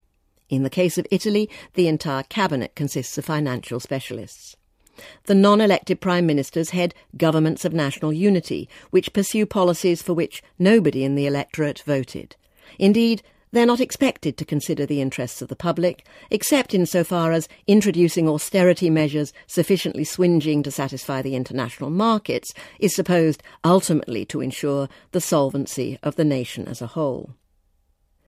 【英音模仿秀】偏信则暗，兼听则明 听力文件下载—在线英语听力室